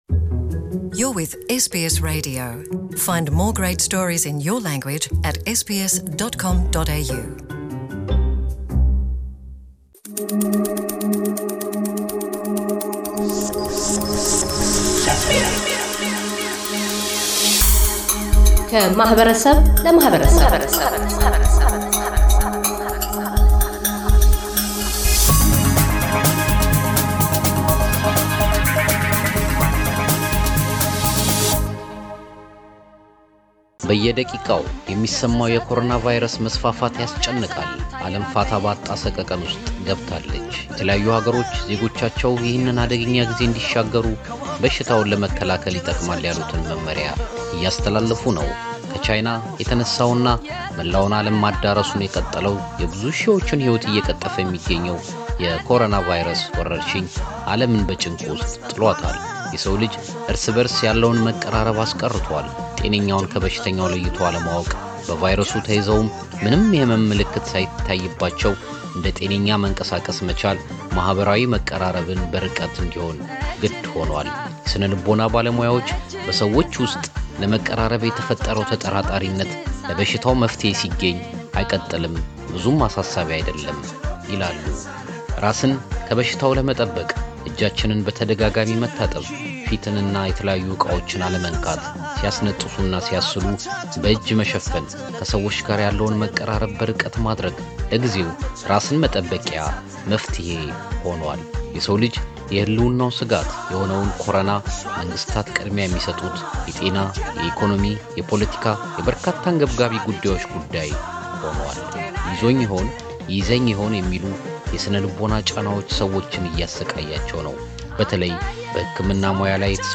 ኮሮናቫይረስ በሕክምና ባለሙያዎች ላይ እያሳደረ ያለው ተፅዕኖ በኢትዮጵያውያን - አውስትራሊያውያን ነርሶች አንደበት